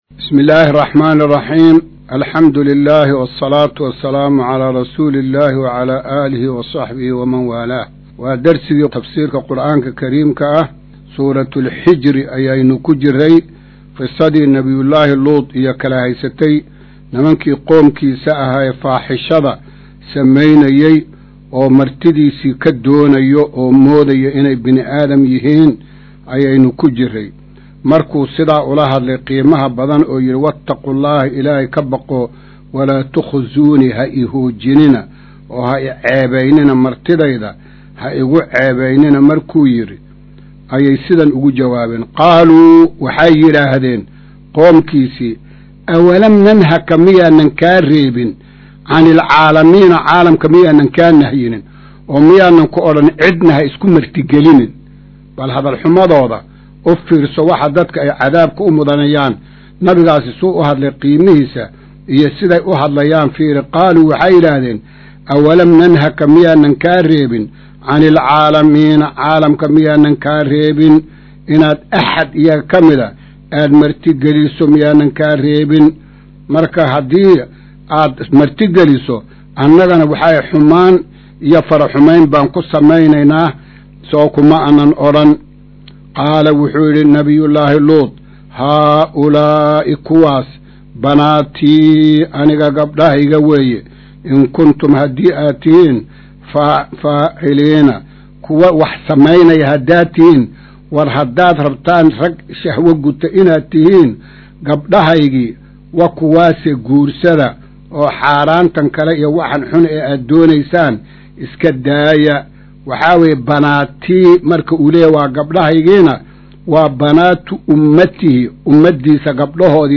Casharka-130aad-ee-Tafsiirka.mp3